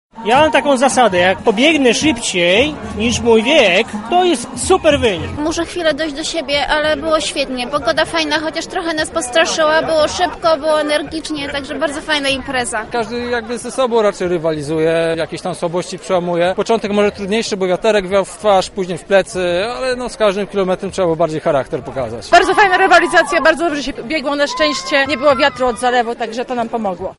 O wrażeniach z biegu mówili sami uczestnicy.
Sonda
Sonda.mp3